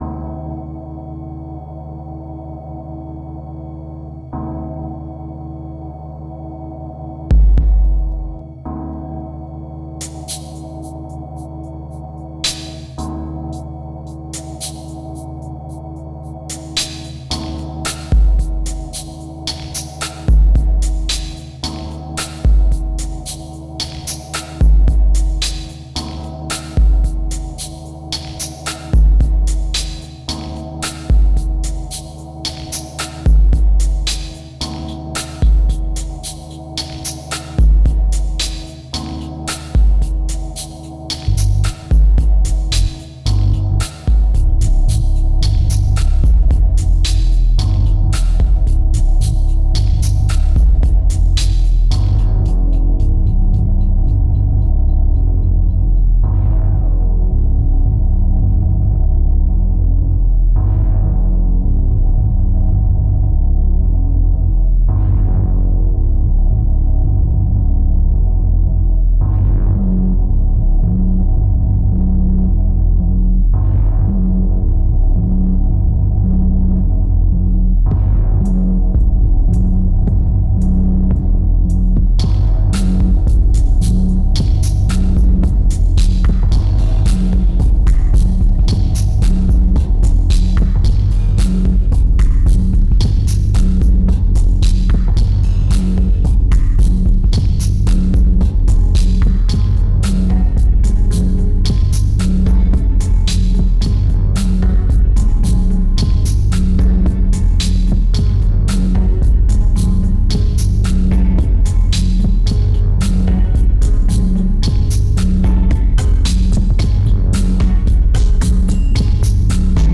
2265📈 - -51%🤔 - 111BPM🔊 - 2010-06-22📅 - -354🌟